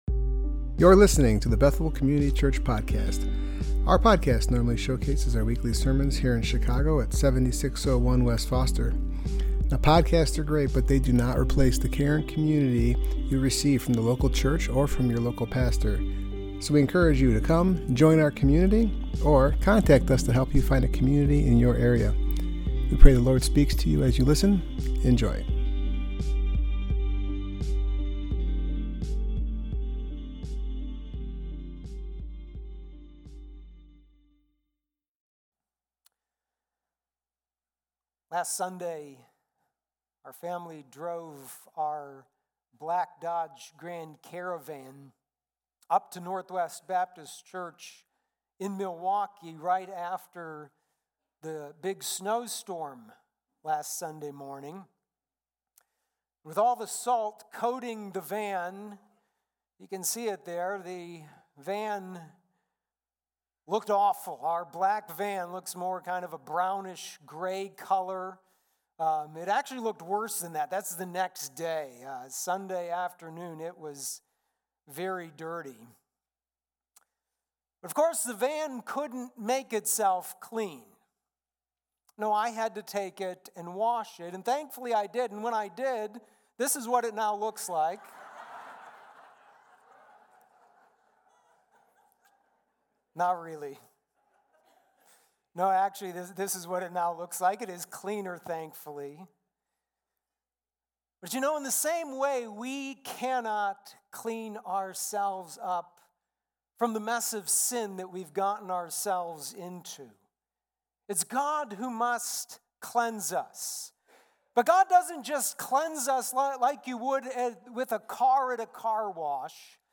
Titus 3:3-8 Service Type: Worship Gathering Topics: Regeneration